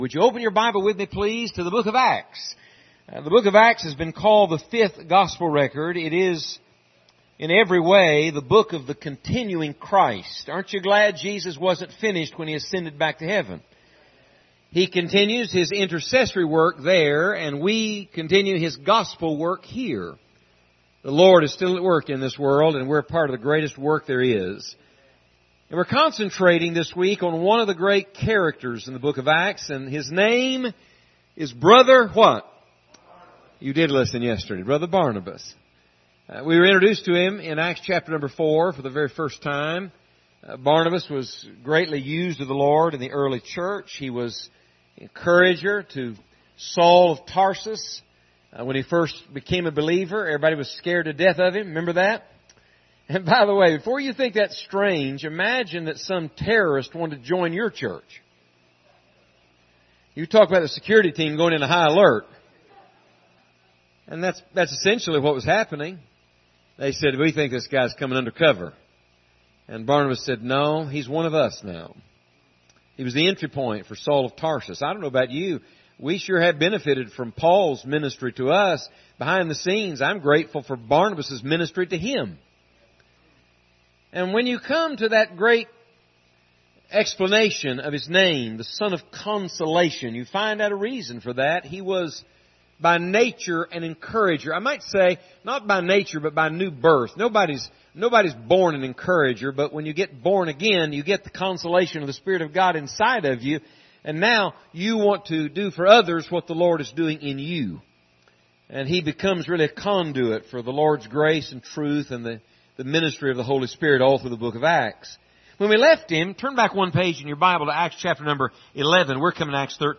Acts 13:1-7 Service Type: Revival Service Topics: serving God « Who’s Son is He?